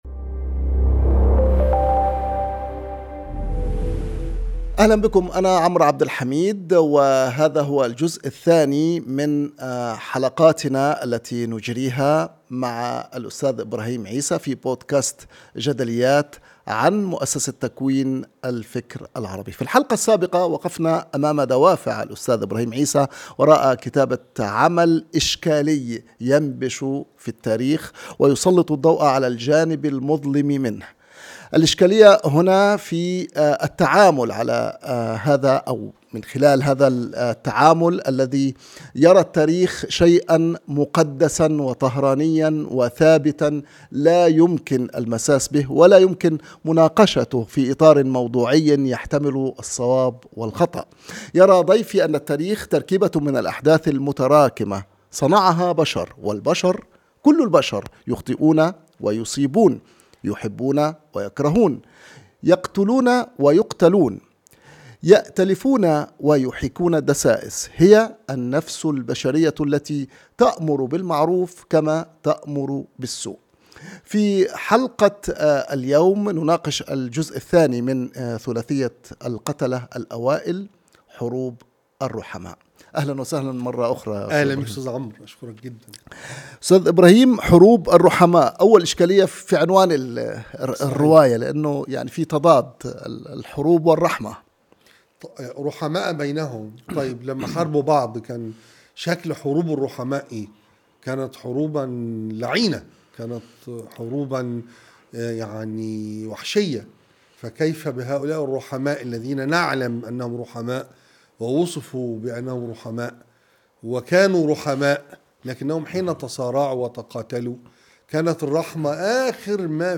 في هذه الحلقة من بودكاست جدليات مع عمرو عبد الحميد يتحدث الإعلامي والروائي المصري إبراهيم عيسى عن الجزء الثاني من روايته حروب الرحماء-القتلة الأوائل. وحروب الرحماء هي مرحلة خلاف السيدة عائشة وطلحة والزبير مع علي بن أبي طالب، وسفر علي ليلحق بجيش عائشة الذي ذهب للبصرة فيما يعرف تاريخيا بموقعة الجمل.